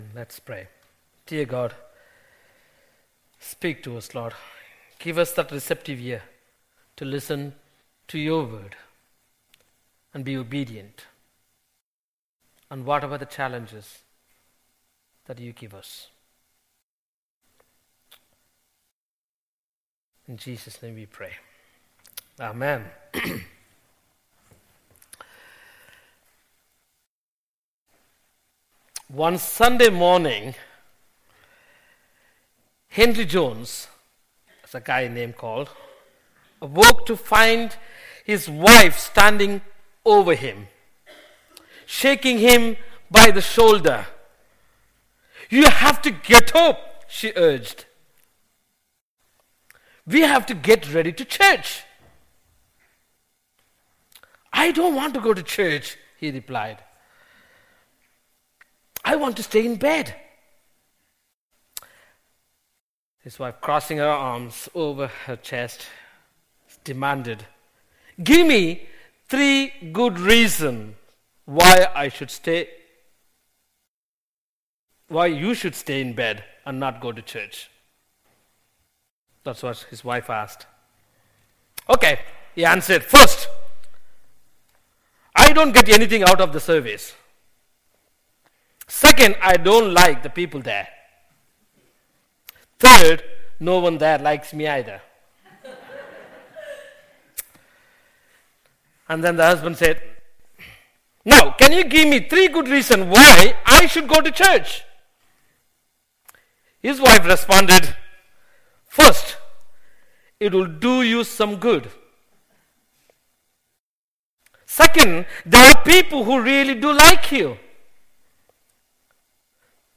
The sermon is also available as an audio file.
11-17-sermon.mp3